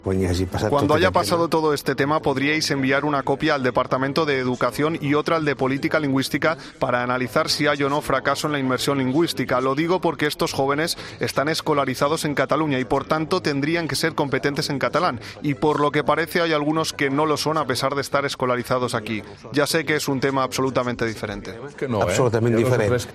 Declaraciones de Josep Lluis Carod Rovira